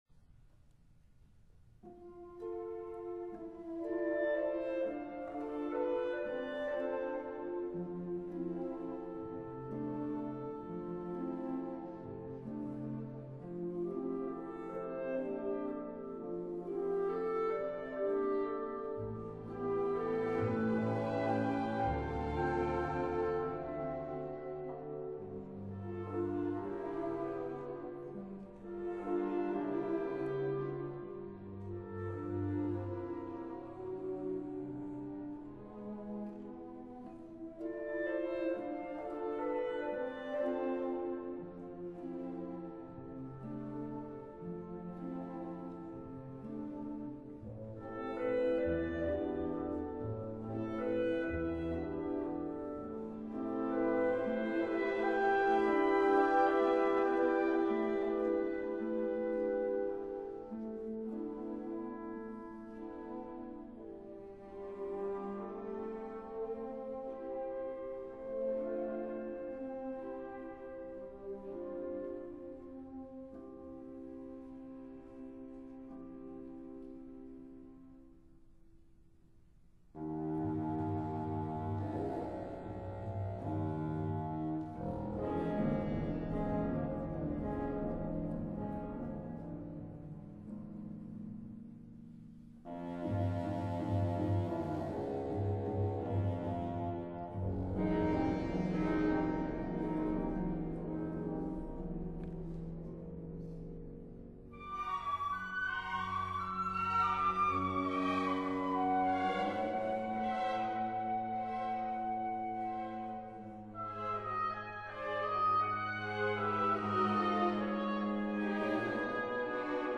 Mouvement de valse[64K WMA